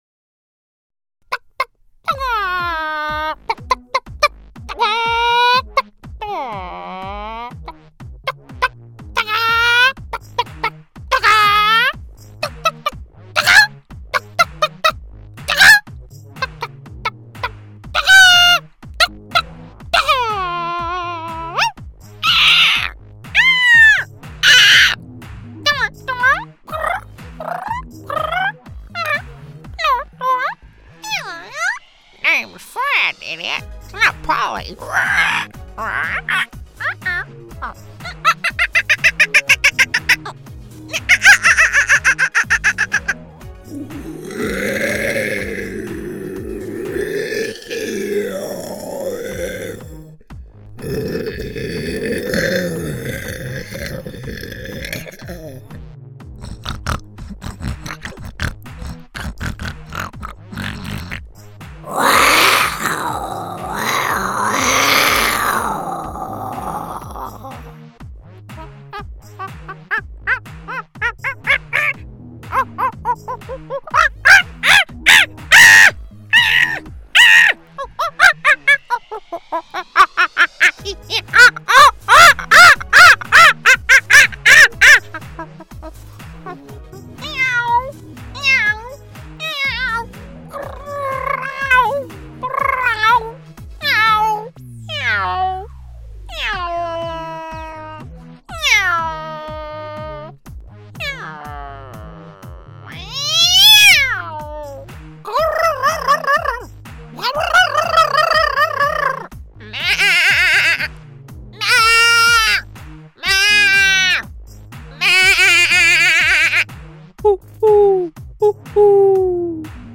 Adult
southern us | natural
quirky